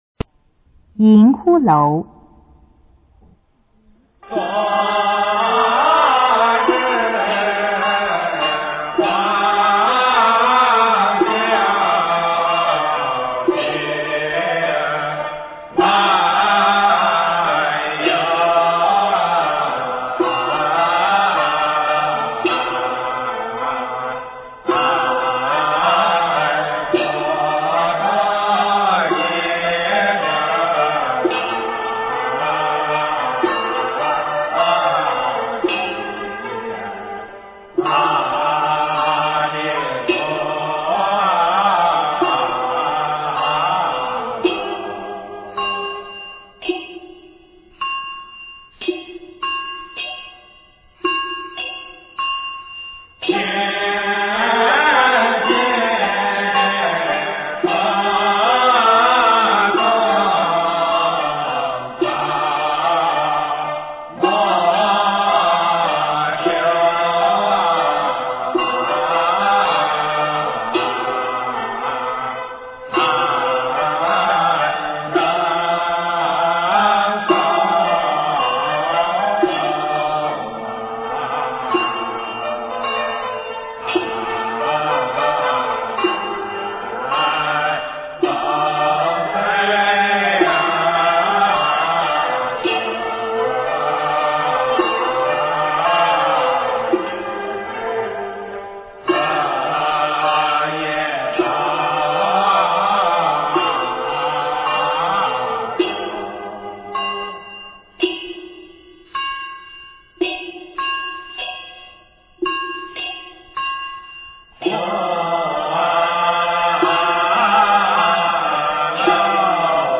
中国江南体育场馆中国有限公司 音乐 全真正韵 银骷髅